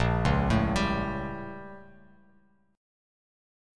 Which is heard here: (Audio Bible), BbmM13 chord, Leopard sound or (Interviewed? BbmM13 chord